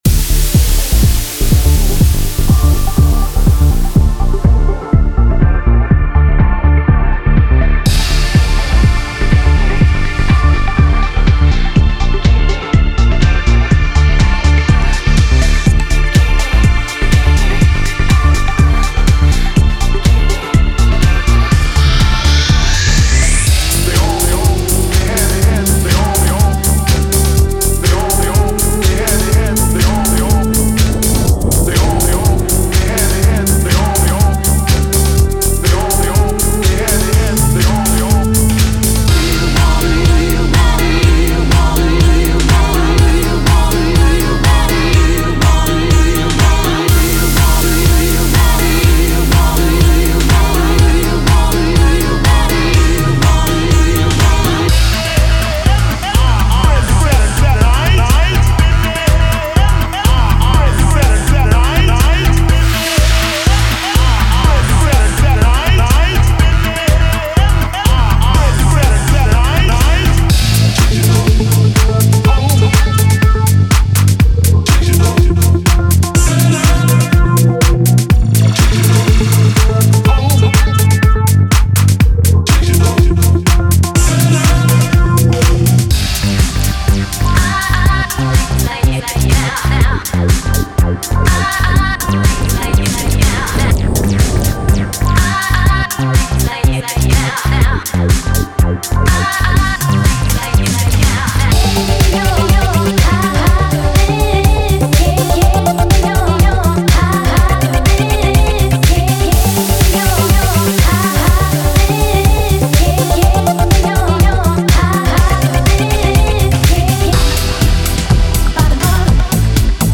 123 bpm
10 House song construction kits